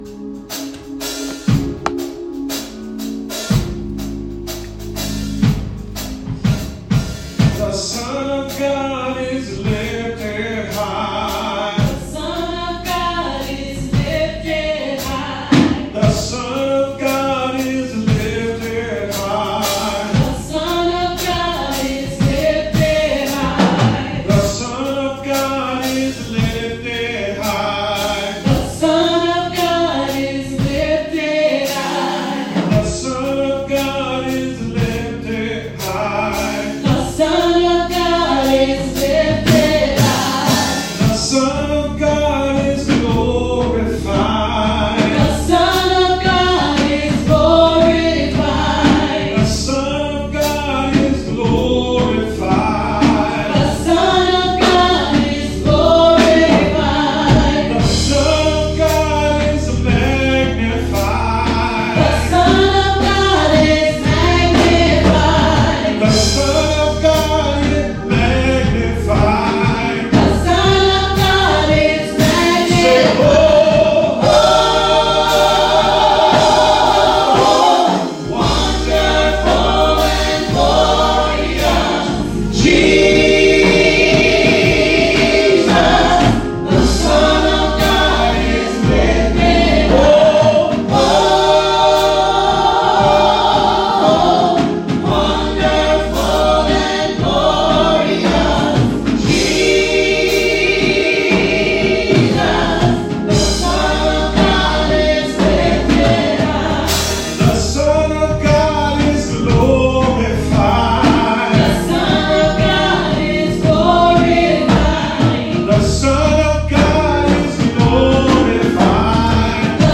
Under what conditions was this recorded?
Welcome to our Worship Service Online